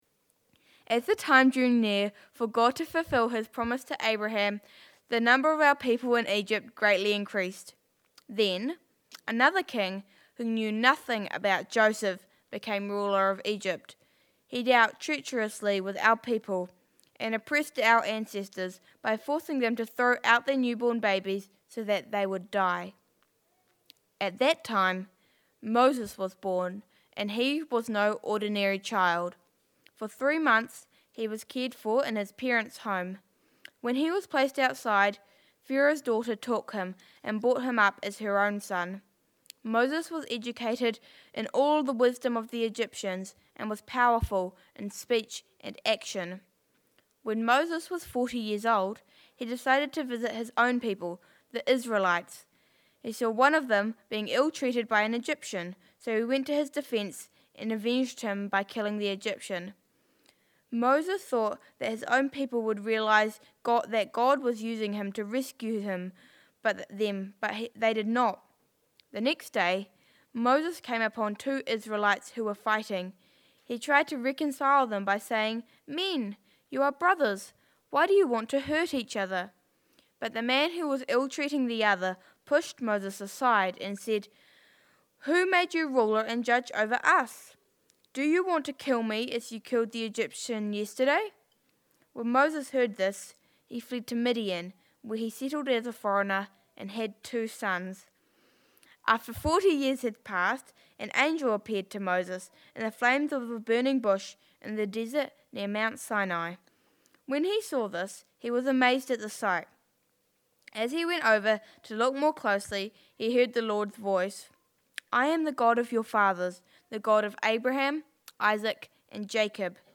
Sermons | All Saints Parish Palmerston North